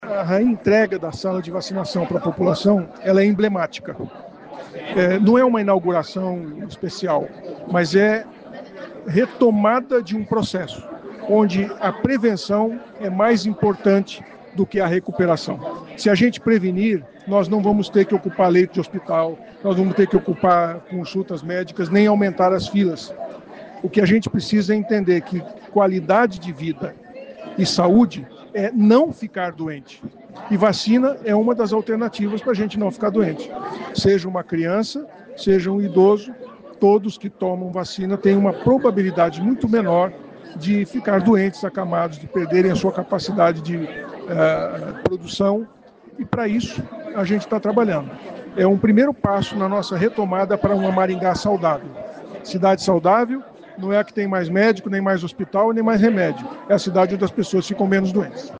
Prefeito diz que reabertura da Sala de Vacinas é emblemática. A proposta é investir cada vez mais na prevenção para reduzir as doenças e tornar Maringá mais saudável.